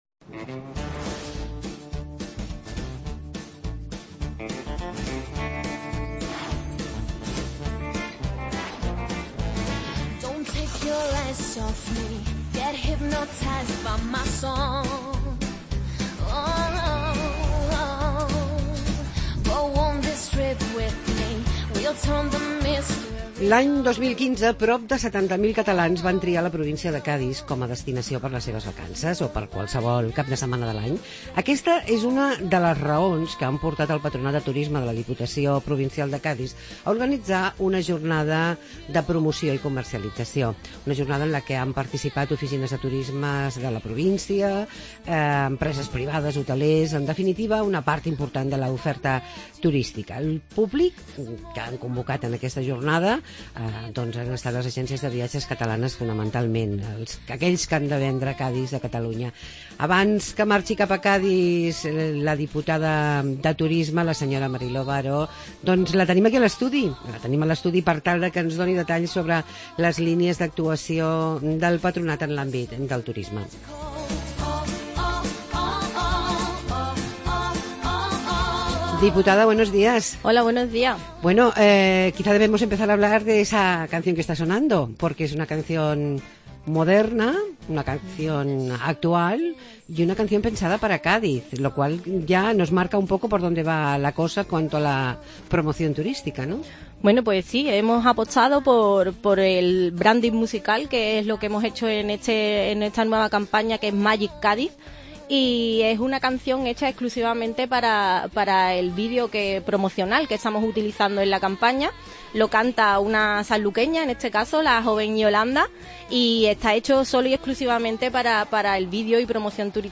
Entrevista con la Diputada de Turismo de Cádiz, Mari Loli Varo.